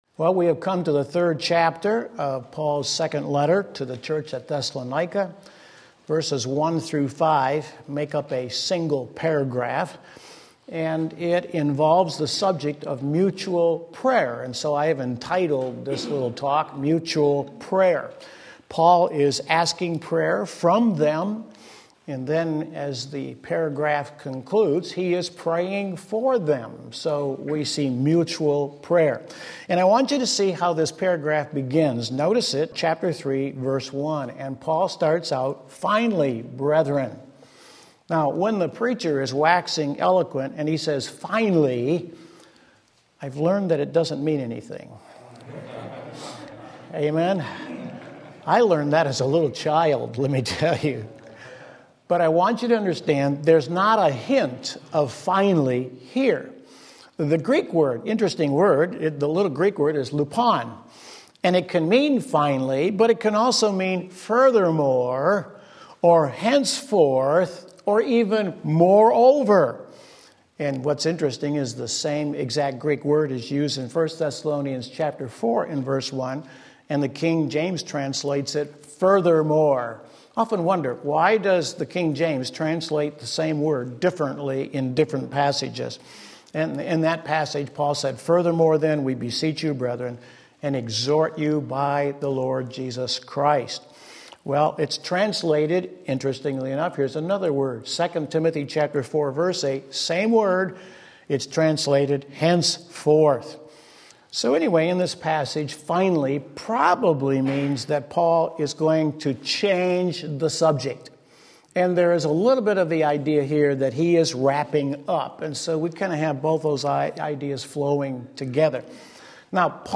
Mutual Prayer 2 Thessalonians 3:1-5 Sunday School